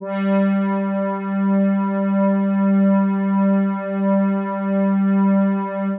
G3_trance_pad_1.wav